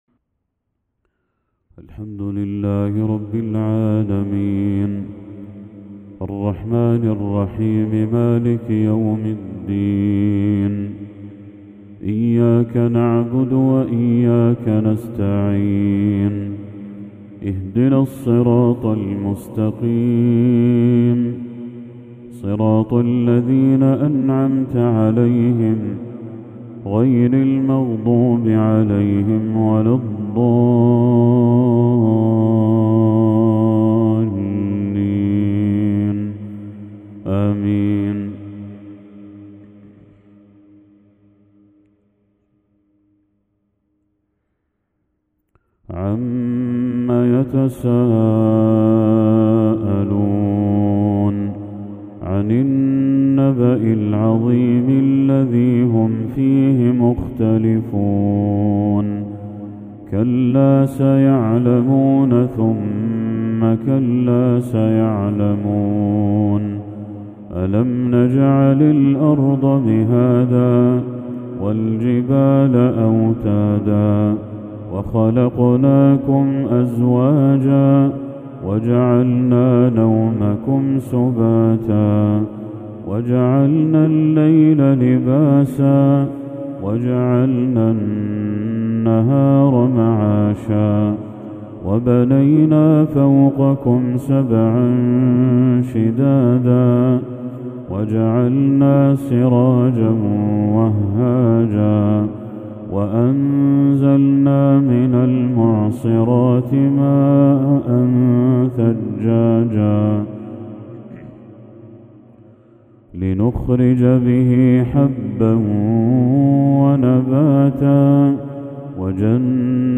تِلاوة بترتيل خاشع للشيخ بدر التركي سورة النبأ كاملة | عشاء 6 ذو الحجة 1445هـ > 1445هـ > تلاوات الشيخ بدر التركي > المزيد - تلاوات الحرمين